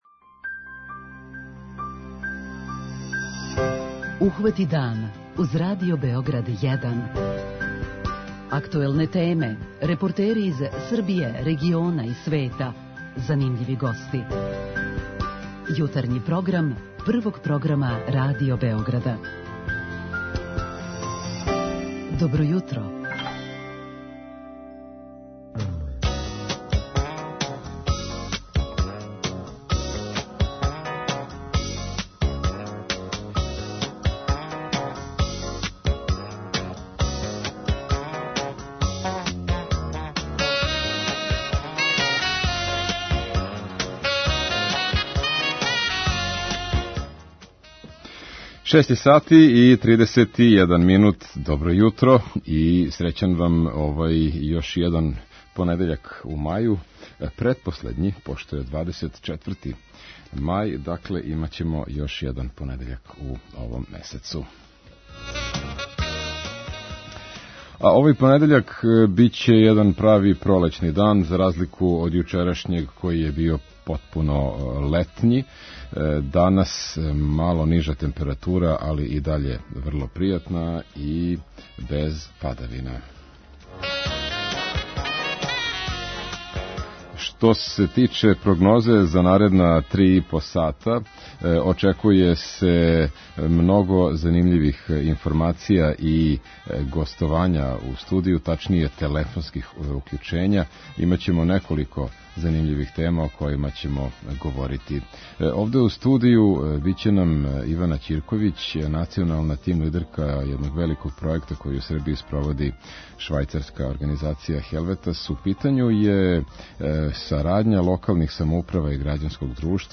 Како мотивисати грађане да више учествују у доношењу одлука на локалу, једна је од тема којом ћемо се бавити у овом издању јутарњег програма. О томе ћемо причати са слушаоцима у 'Питању јутра'